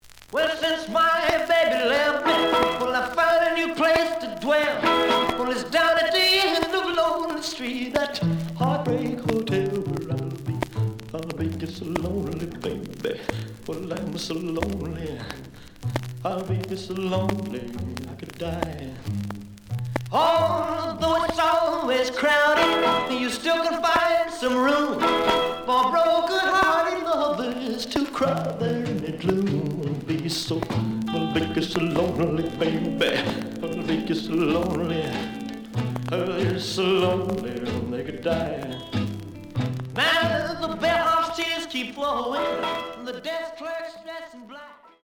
The audio sample is recorded from the actual item.
●Genre: Rhythm And Blues / Rock 'n' Roll
Some click noise on A side due to scratches.